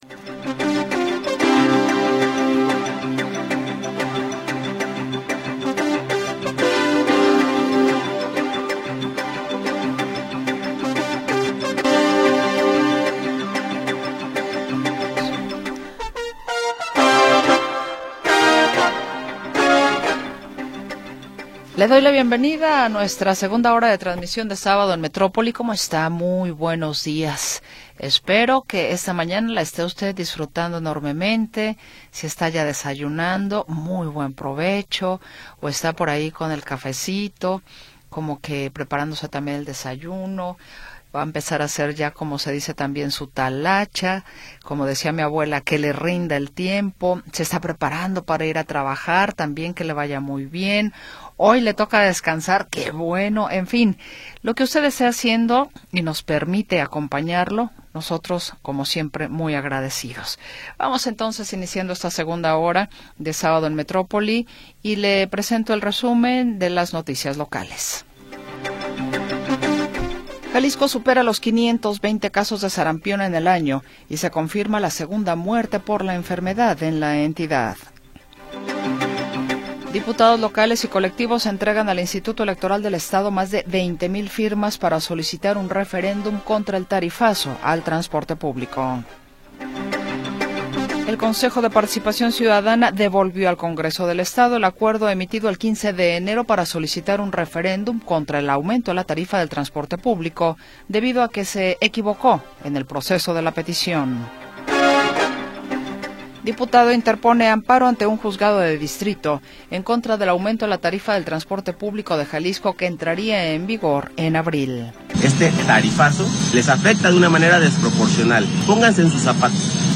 Segunda hora del programa transmitido el 24 de Enero de 2026.